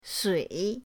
sui3.mp3